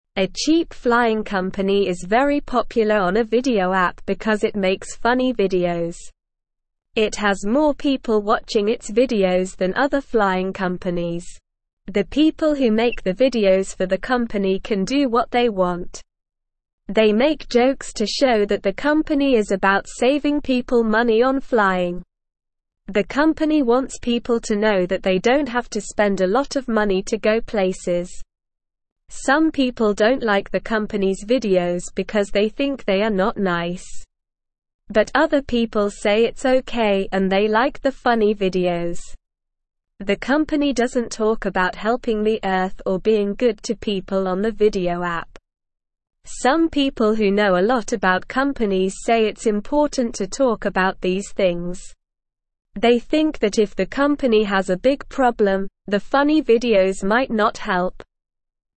Slow
English-Newsroom-Beginner-SLOW-Reading-Funny-Flying-Company-Saves-Money-with-Popular-Videos.mp3